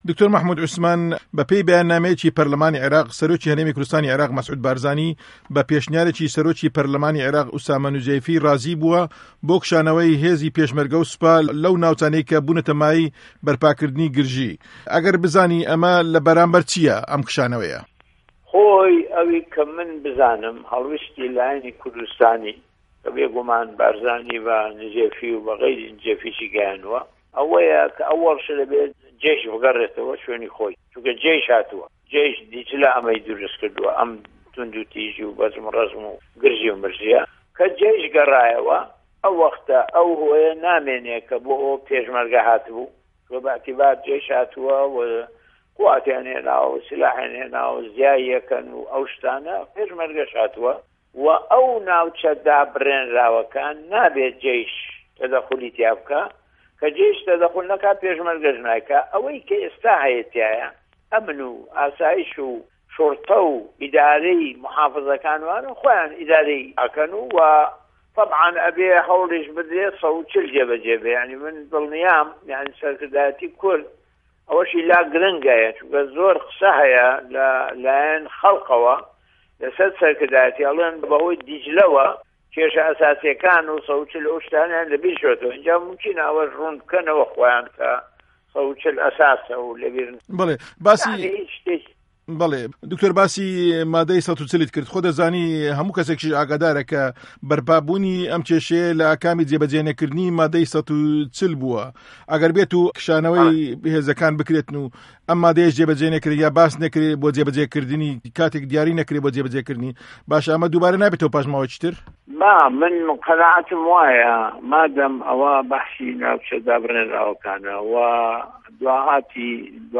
وتووێژی دکتۆر مه‌حمود عوسمان